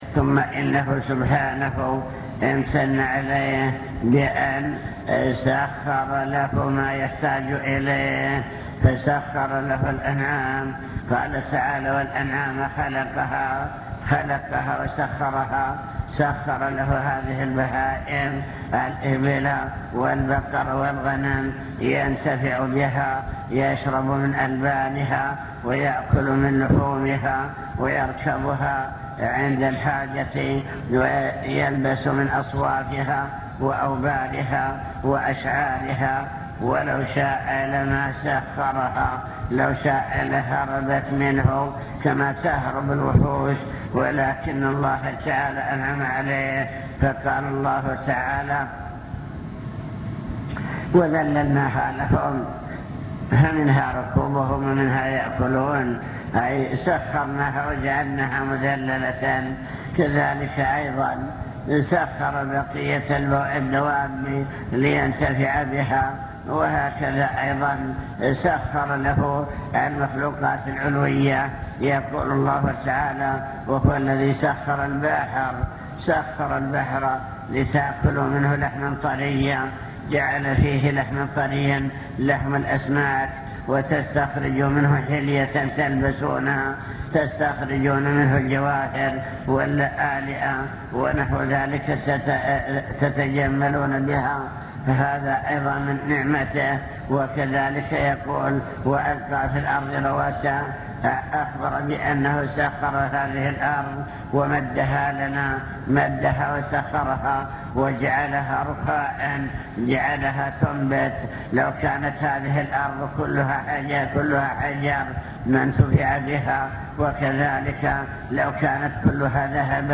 المكتبة الصوتية  تسجيلات - محاضرات ودروس  محاضرة بعنوان شكر النعم (2) امتنان الله تعالى على عباده بما أنعم عليهم